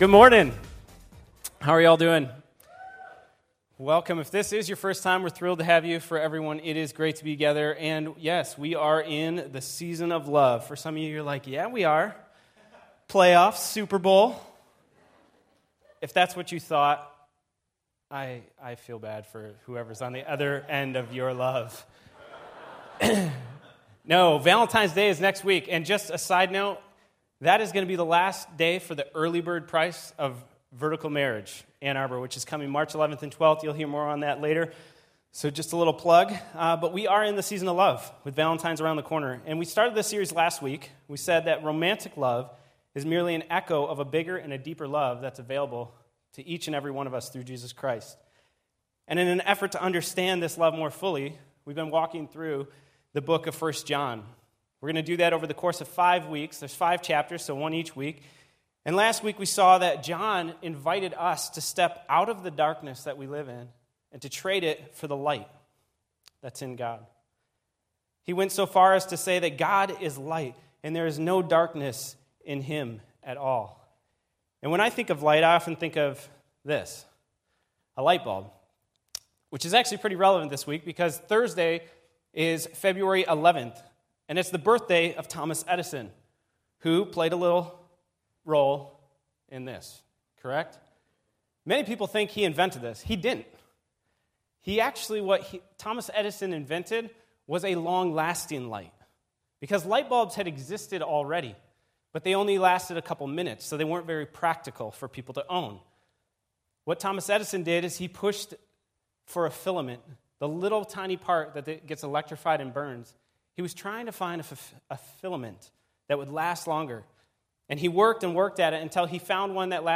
Audio Sermon Save Audio Save PDF https